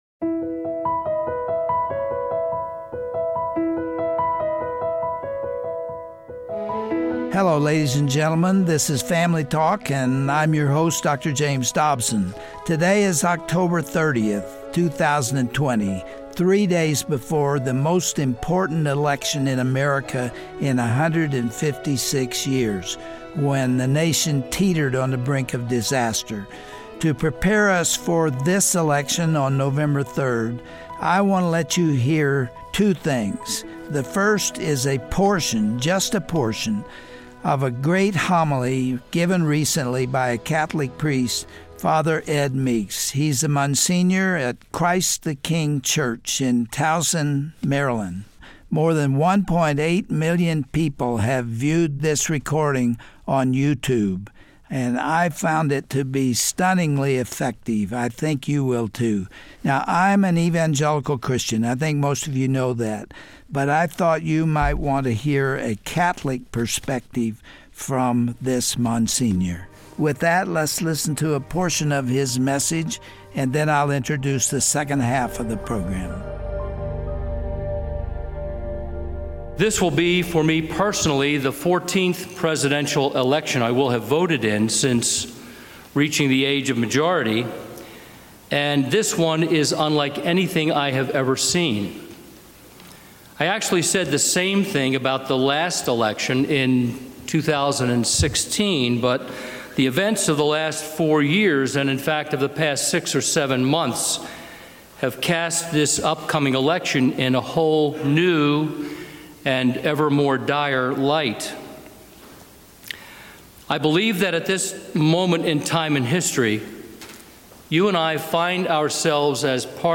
He then reads from his October newsletter, explaining the issues that are at stake.